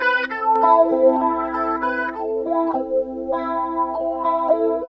70 GTR 3  -R.wav